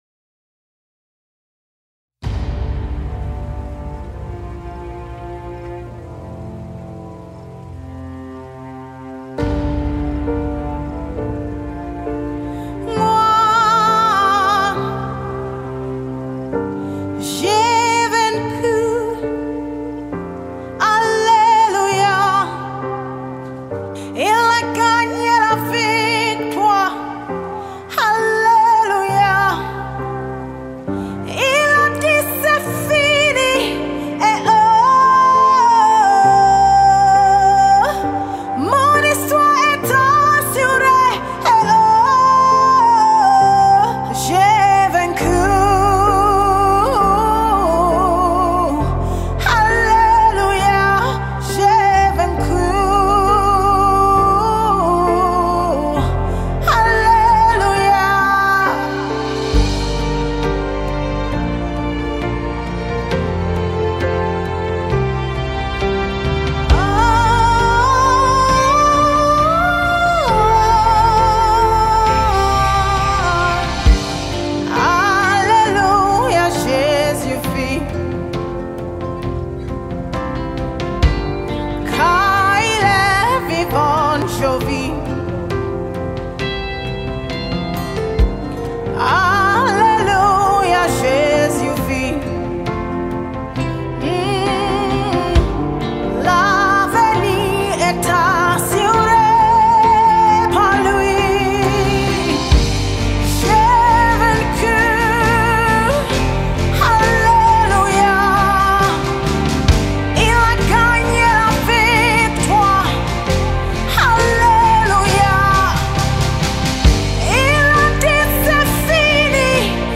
a soulful melody that lifts your spirit
Genre:Gospel